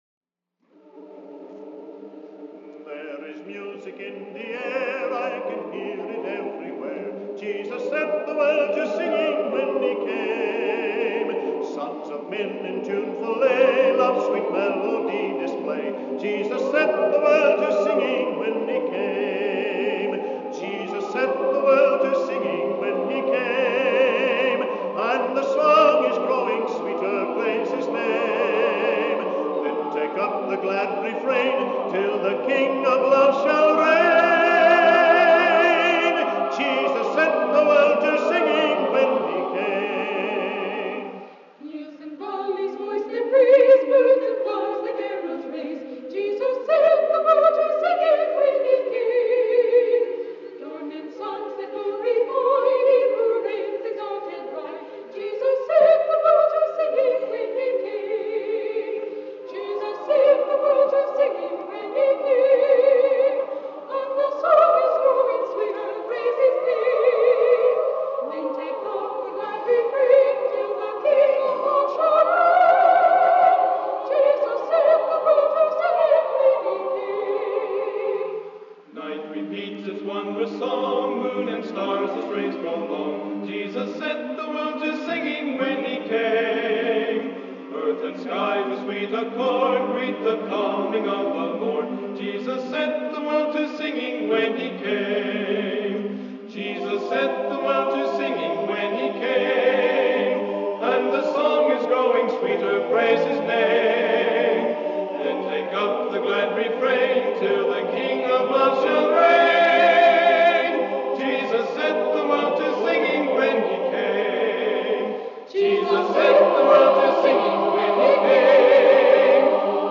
This is a direct-to-disc recording of the Bethany Nazarene College A Cappella Choir Annual Tour from 1962-1963 year.